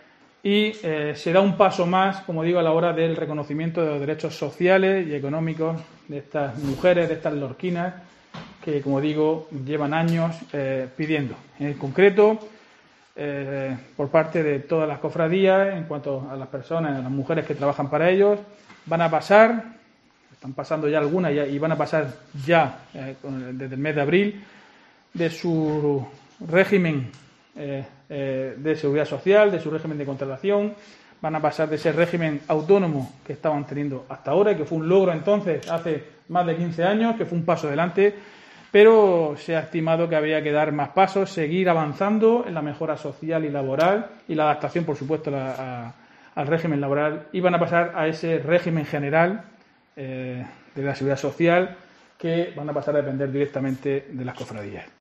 Diego José Mateos, alcalde de Lorca sobre bordadoras